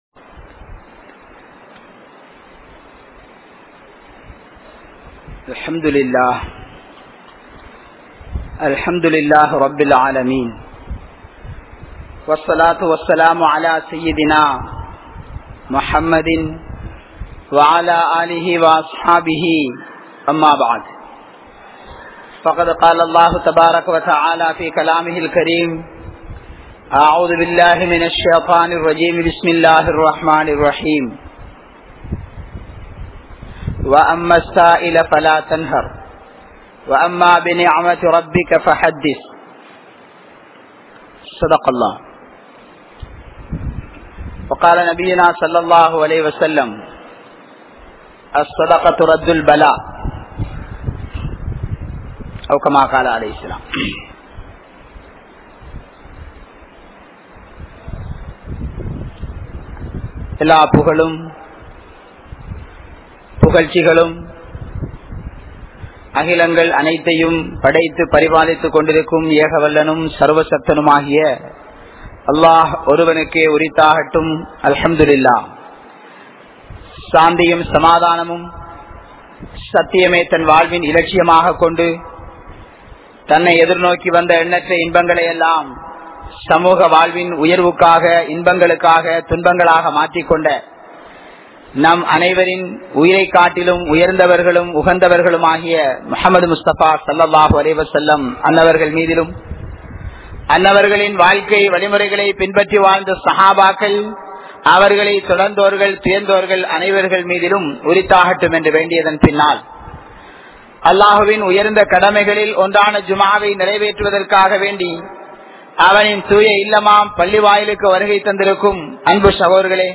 Sakthi Vaaintha Sathaqa (சக்தி வாய்ந்த ஸதகா) | Audio Bayans | All Ceylon Muslim Youth Community | Addalaichenai